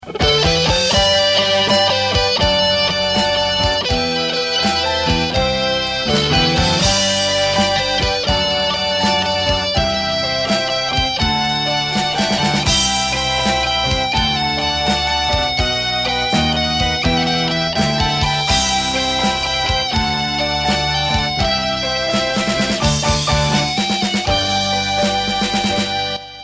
MPEG file of the instrumental introduction (414kb)